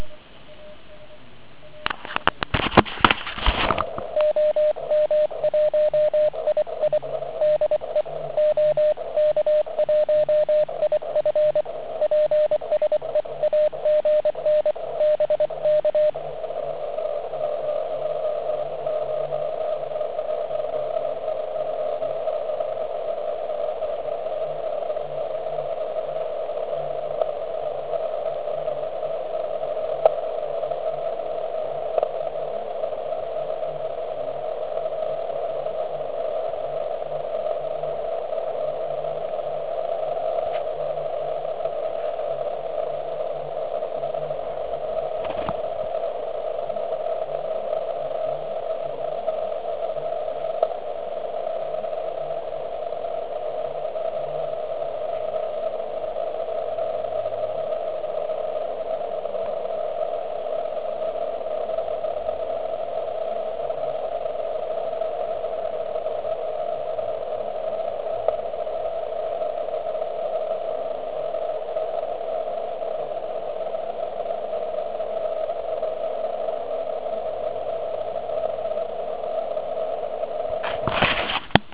Na volacím kmitočtu 3560 KHz se vyskytuje s nezvyklou silou nějaký ON4. Signál má takový divný charakter.
Signál postupně slábne, až zmizí natrvalo.
Škoda, že jsem nahrávku nestihl udělat asi o 30 sec dříve.